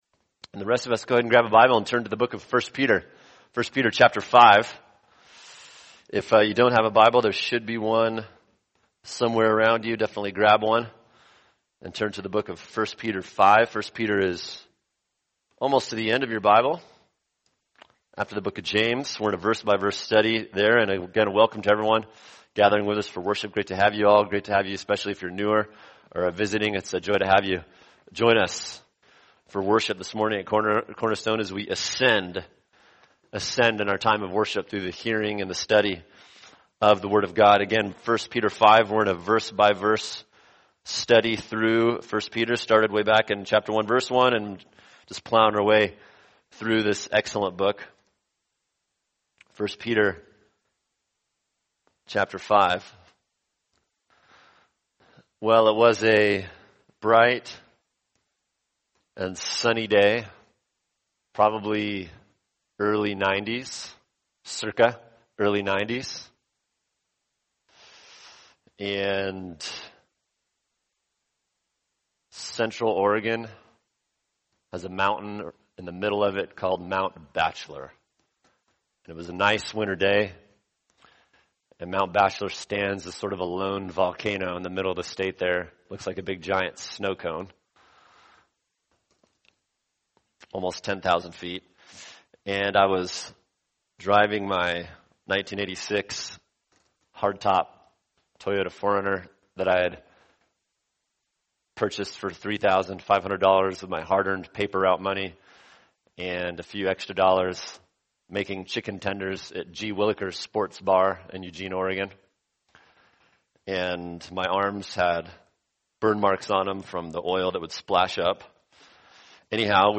[sermon] 1 Peter 5:5c-7 Why We Must Be Humble | Cornerstone Church - Jackson Hole